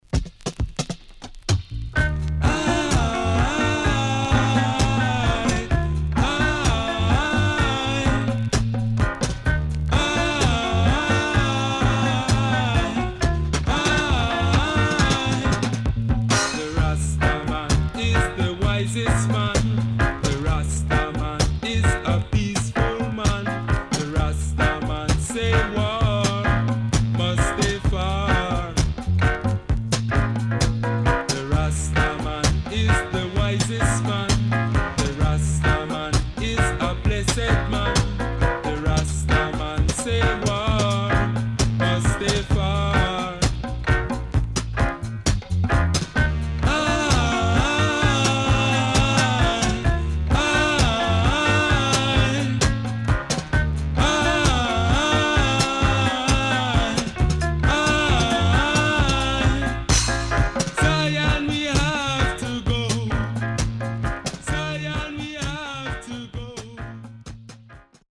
HOME > Back Order [VINTAGE DISCO45]  >  KILLER & DEEP
W-Side Good Roots
SIDE A:所々チリノイズがあり、少しプチノイズ入ります。